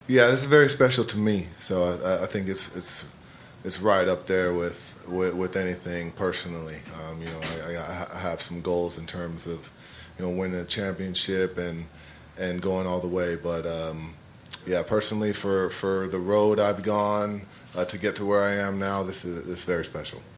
mlb-all-star-game-post-special-to-me-giancarlo-stanton.mp3